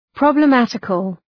Προφορά
{,prɒblə’mætıkəl}